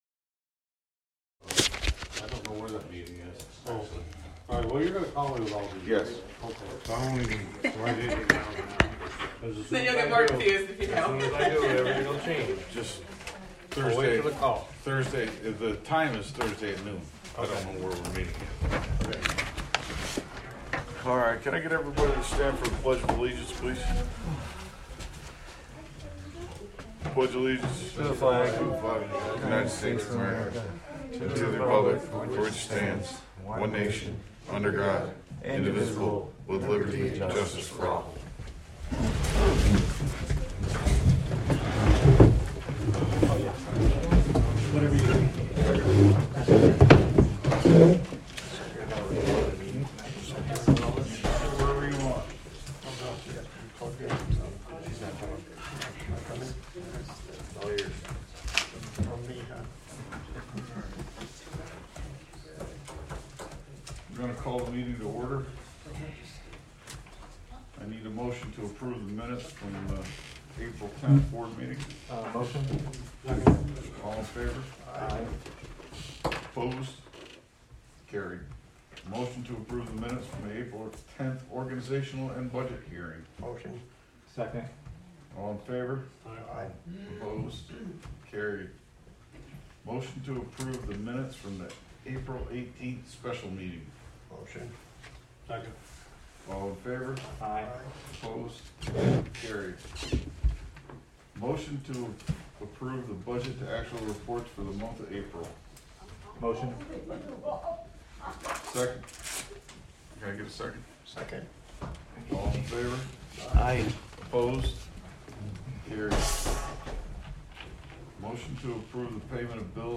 Live from the Village of Philmont